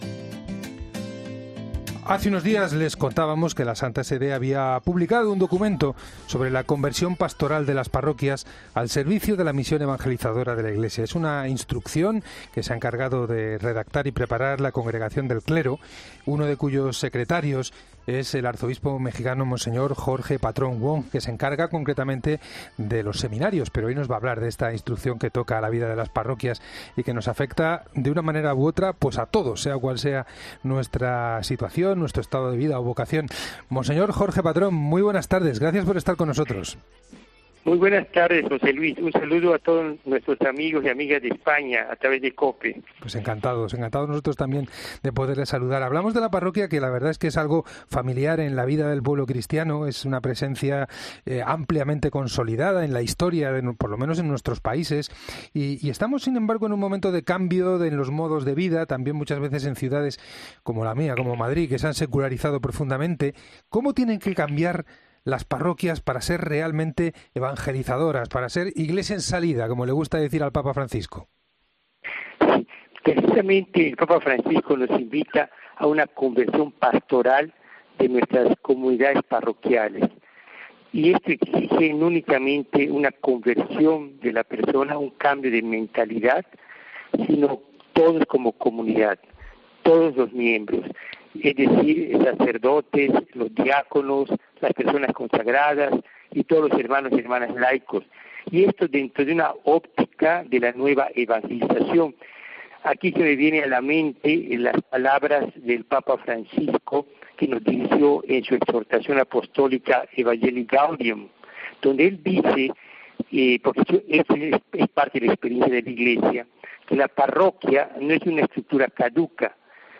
El Secretario para los seminarios de la Congregación para el Clero cuenta en los micrófonos de la Cadena COPE en qué consiste el documento